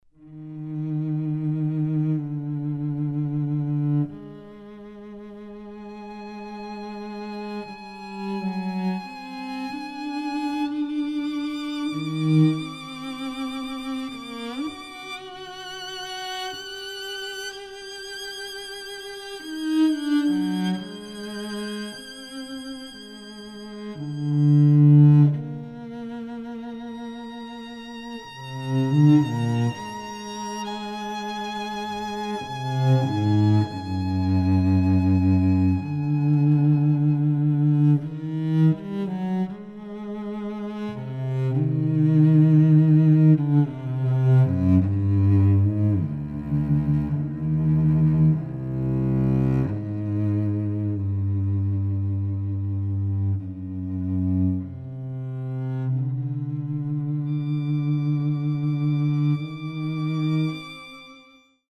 smuikas (violin)
violončelė (cello)
fortepijonas (piano)